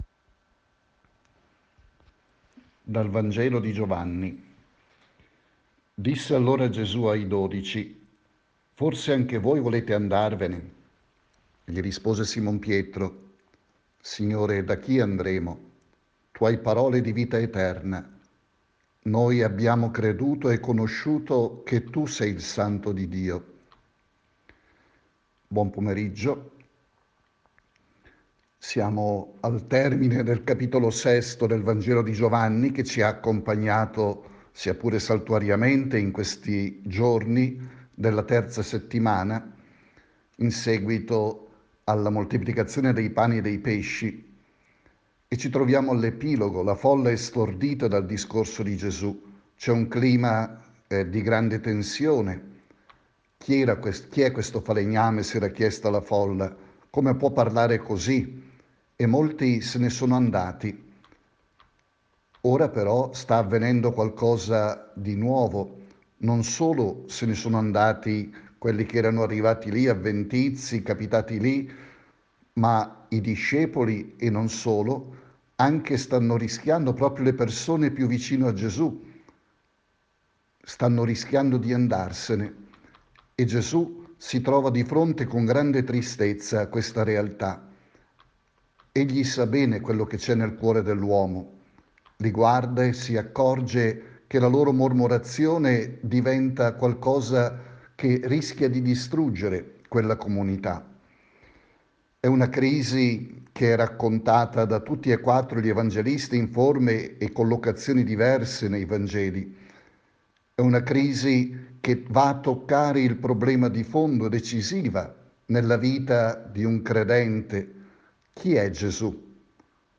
Messaggio